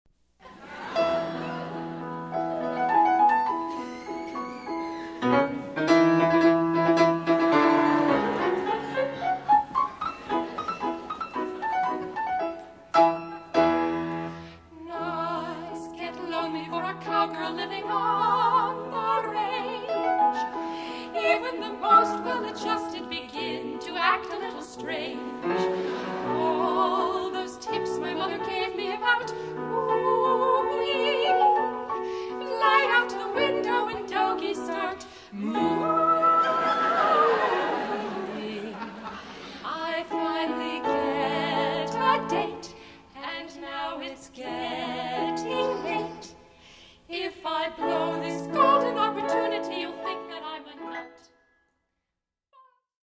--comedy music parody